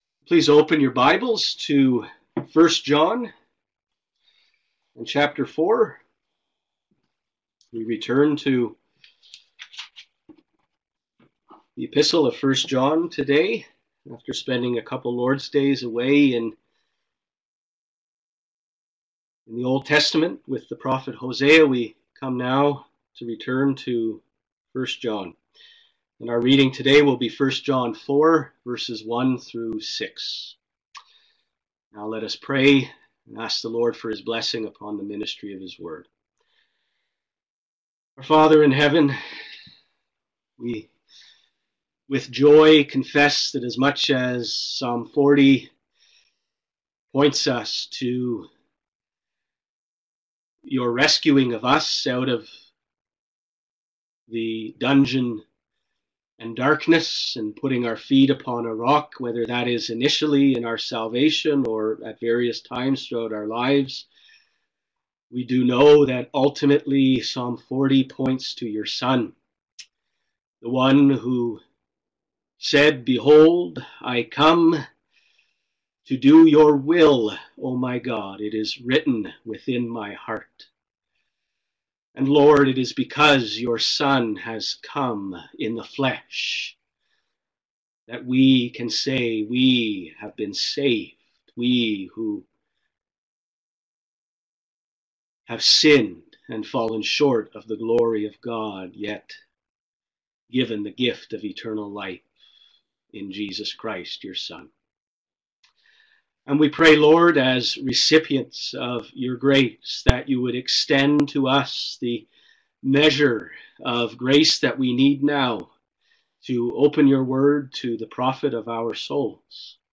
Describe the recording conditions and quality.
Holy Spiritual Discernment! (1 John 4: 1-6) *Live-streamed Recording*